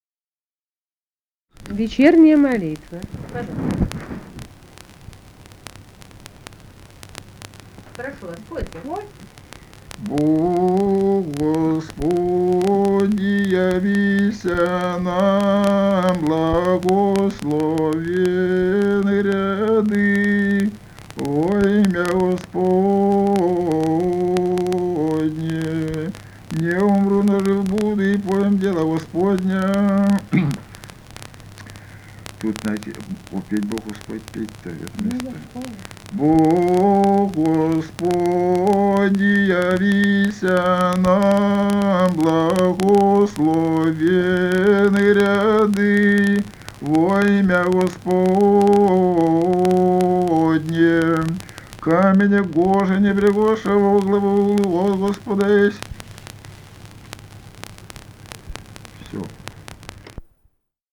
полевые материалы
«Бог Господи, явися нам» (вечерняя молитва).
Архангельская область, с. Койда Мезенского района, 1965, 1966 гг.